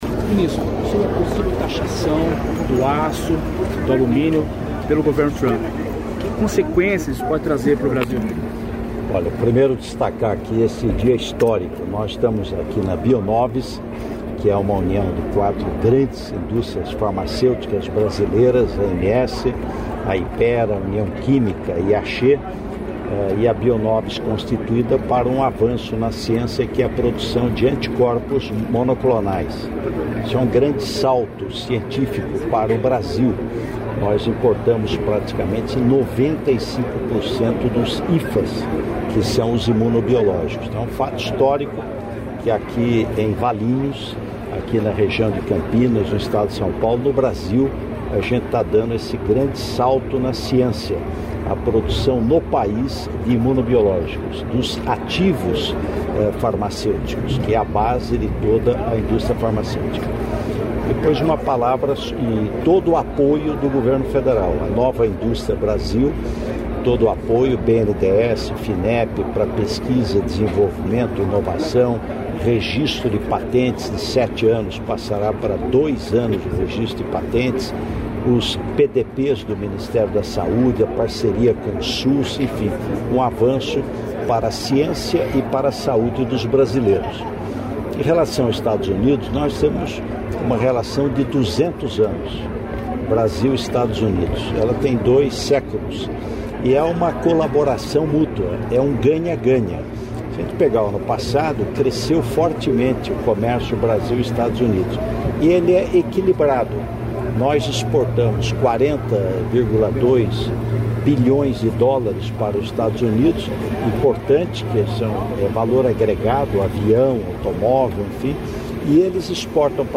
Íntegra da entrevista concedida pelo vice-presidente da República e ministro do Desenvolvimento, Indústria, Comércio e Serviços, Geraldo Alckmin, nesta segunda-feira (10), em Valinhos (SP).